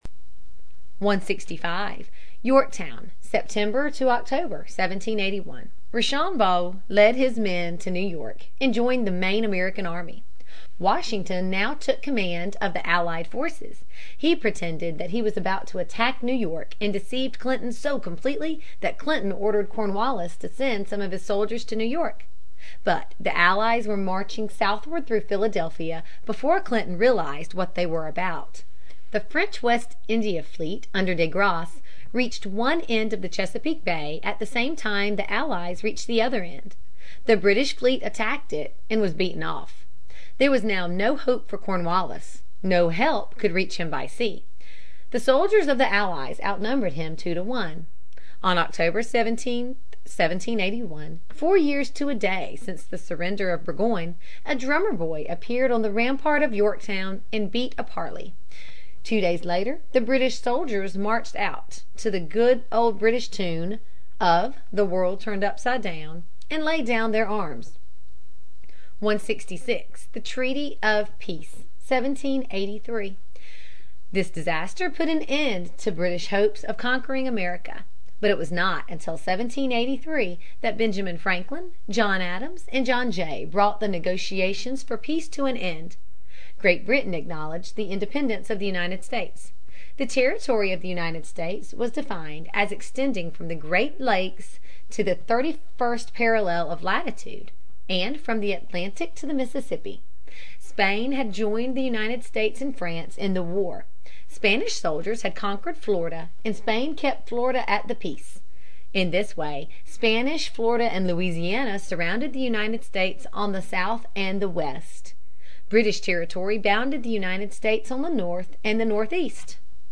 在线英语听力室美国学生历史 第55期:独立(4)的听力文件下载,这套书是一本很好的英语读本，采用双语形式，配合英文朗读，对提升英语水平一定更有帮助。